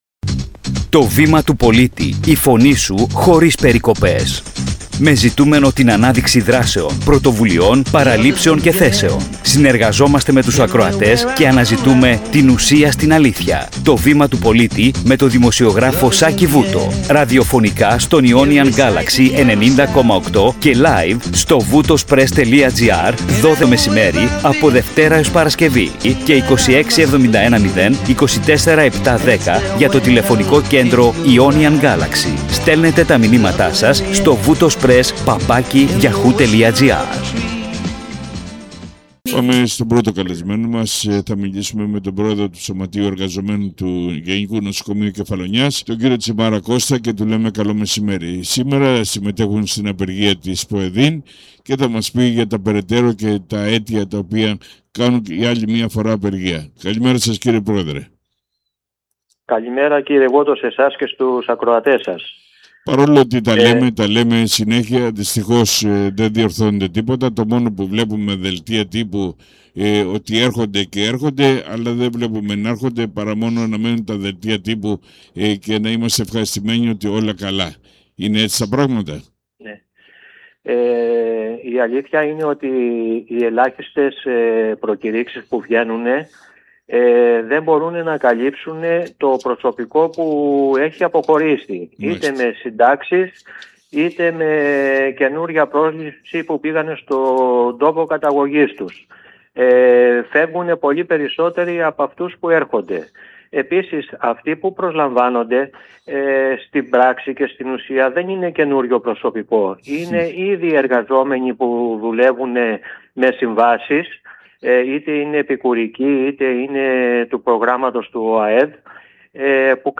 Σύνοψη συνέντευξης
Στη ραδιοφωνική εκπομπή συζητήθηκαν τα σοβαρά προβλήματα υποστελέχωσης και υπολειτουργίας του Γενικού Νοσοκομείου Κεφαλονιάς, με αφορμή την απεργία της ΠΟΕΔΗΝ.